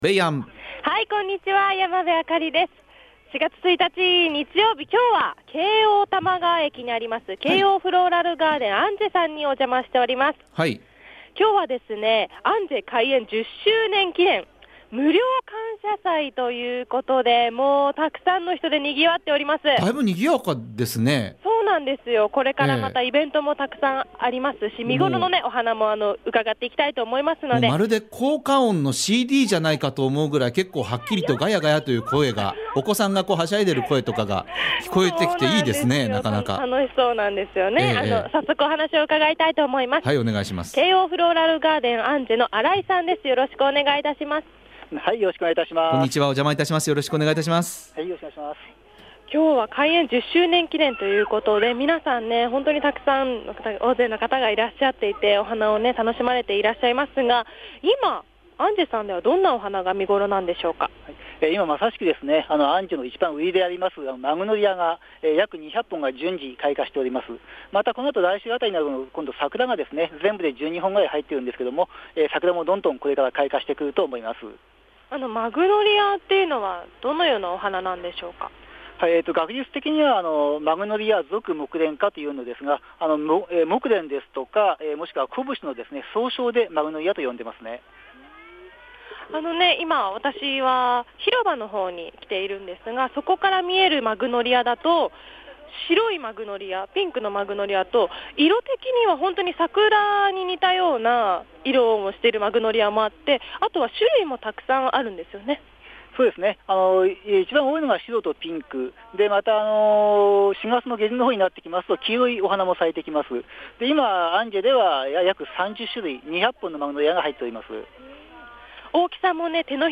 びゅーサン 街角レポート